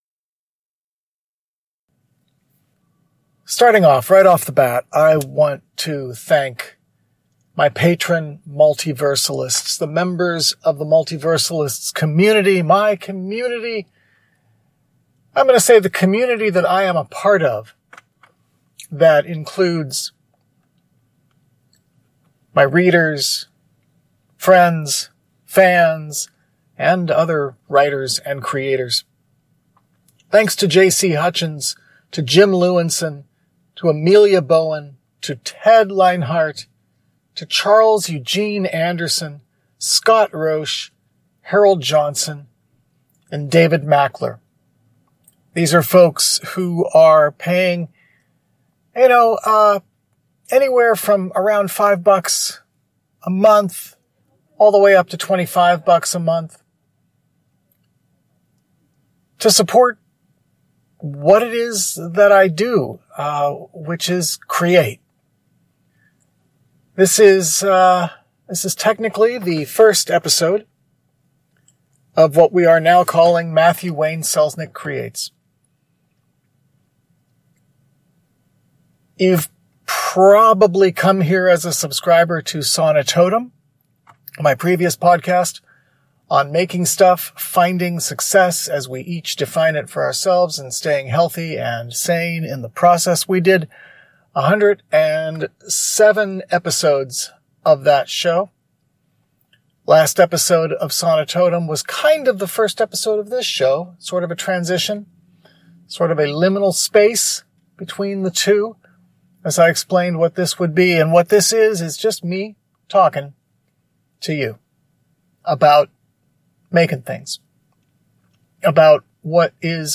I don’t want to take the time for intro music, or interstitials, or bed music, or any of that stuff that requires extra effort and takes extra time. The idea is to come as close as possible to just turning on a mic and talking, and then taking the path of least resistance toward getting it out to you.
The lavalier microphone used to record this episode.